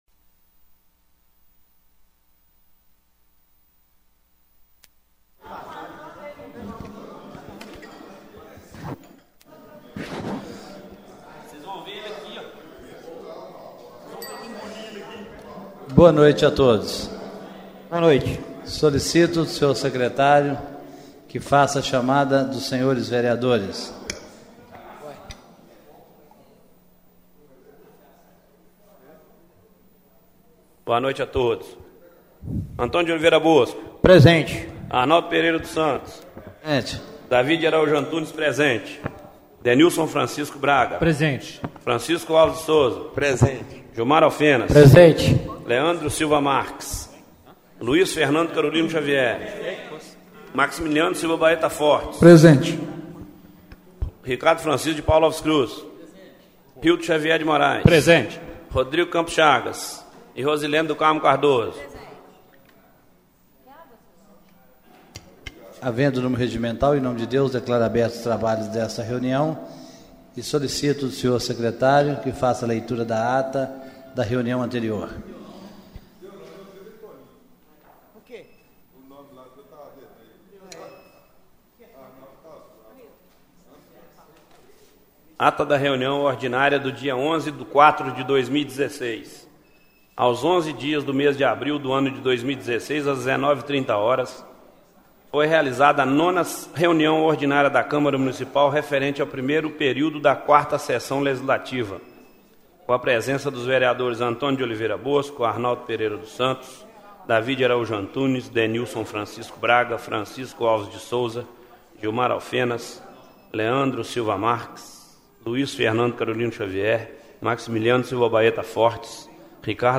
Reunião Ordinária do dia 18/04/2016